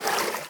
swim1.ogg